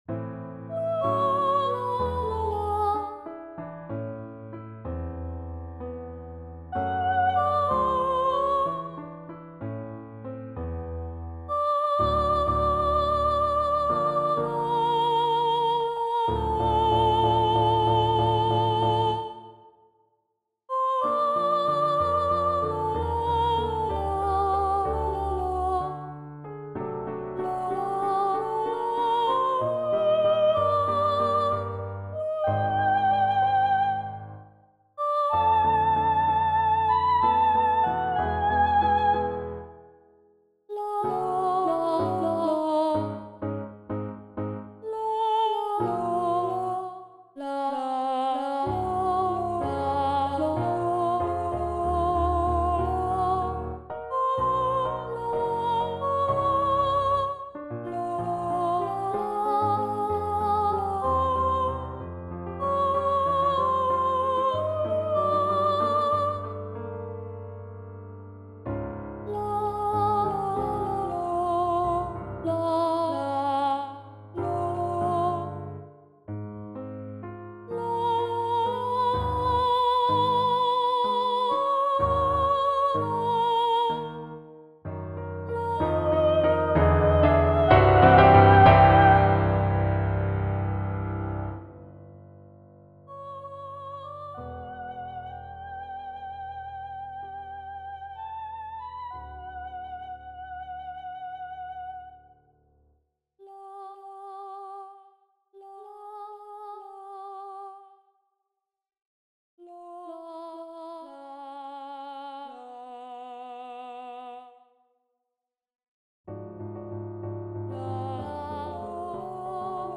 Romance for Mezzo-Soprano or Baritone "O, Lady Inna..."